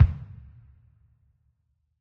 drum.ogg